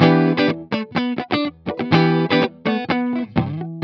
14 GuitarFunky Loop E.wav